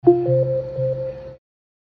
SFX – AMAZON ECHO – DO NOT DISTURB ON
SFX-AMAZON-ECHO-DO-NOT-DISTURB-ON.mp3